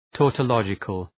Προφορά
{,tɔ:tə’lɒdʒıkəl}